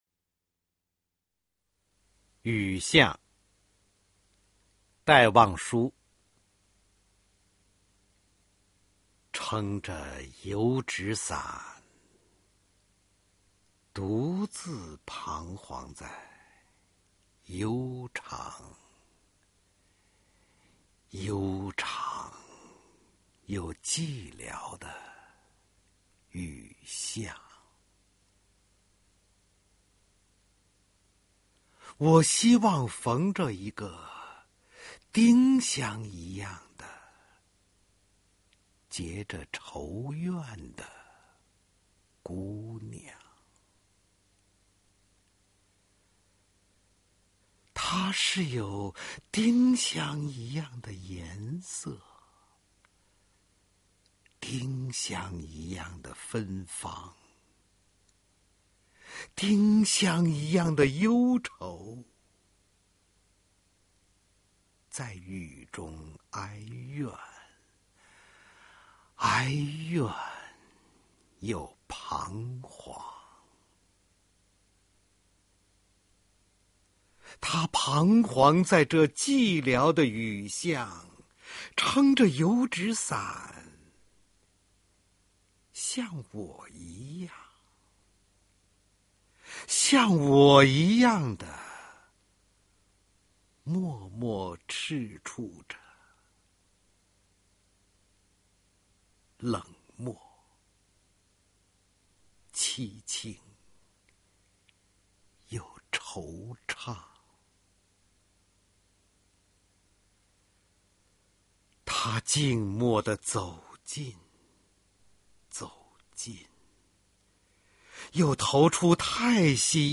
首页 视听 经典朗诵欣赏 网络精选——那些张扬个性的声音魅力
雨巷　/ 戴望舒朗诵：方明